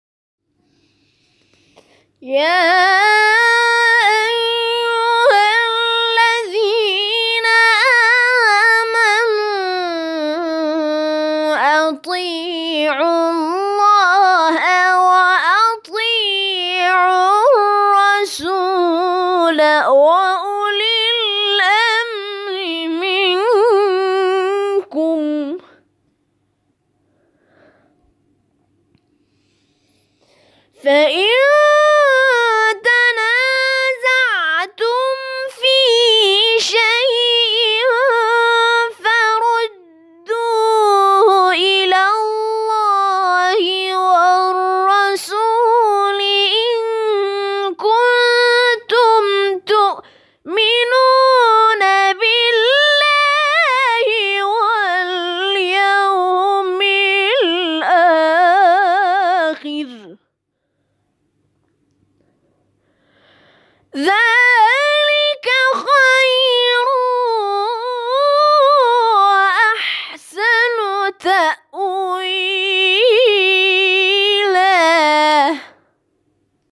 تلاوت نفرات برتر مسابقه از دید داوران:
تلاوت